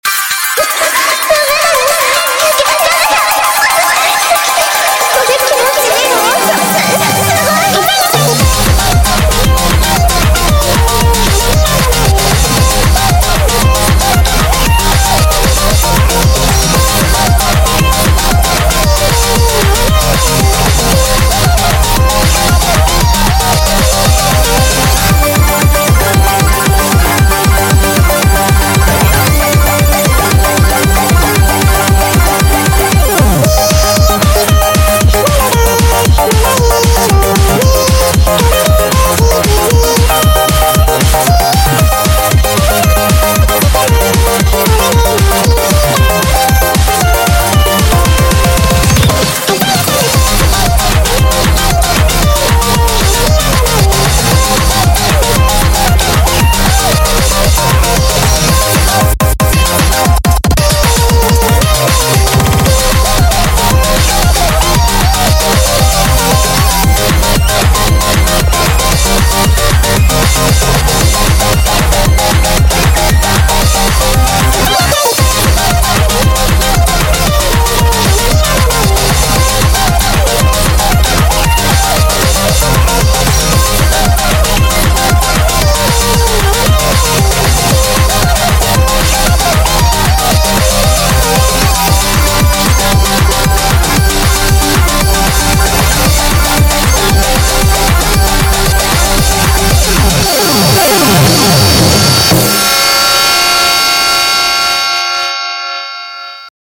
BPM230
Audio QualityPerfect (High Quality)